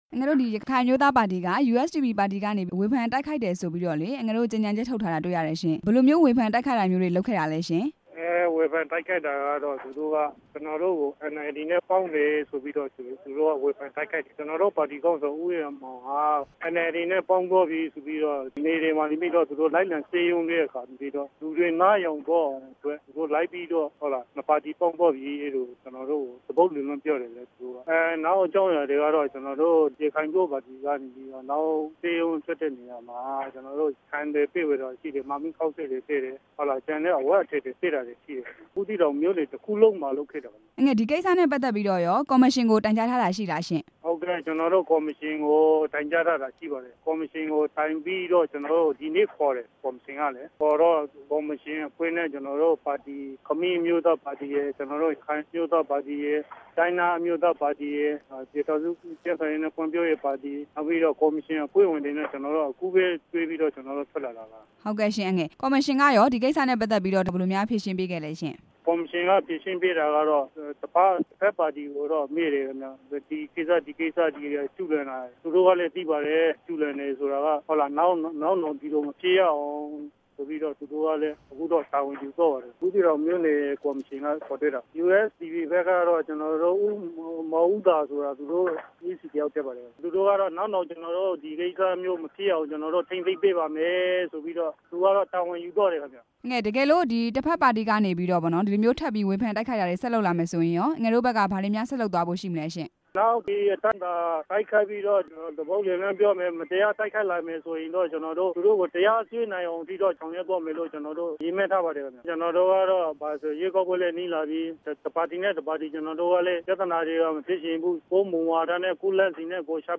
ရခိုင်အမျိုးသားပါတီက ကြေညာချက်ထုတ်ပြန်တဲ့အကြောင်း မေးမြန်းချက်